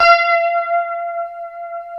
F#5 HSTRT MF.wav